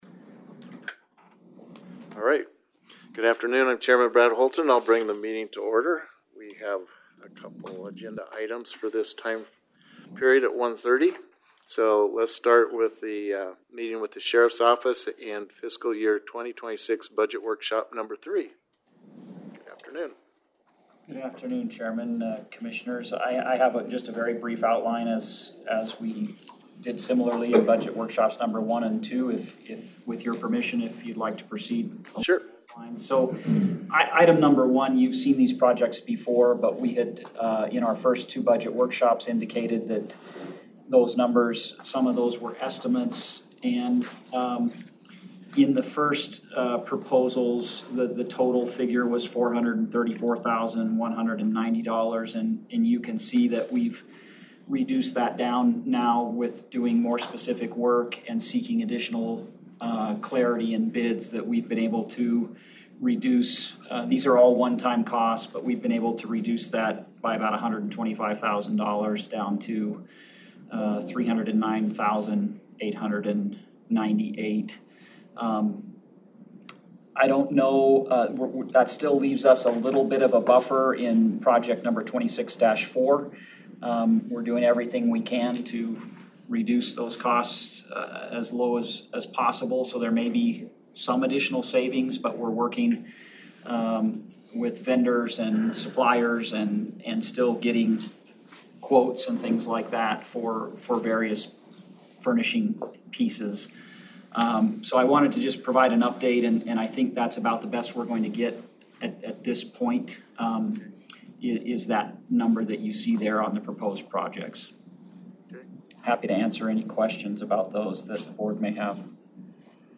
Meeting with the Sheriff's Office • FY2026 budget workshop no. 3 • Pond Lane female detention facility Supporting Documents: Event linked to: on 0001-01-01 Click here to view all linked supporting documentation.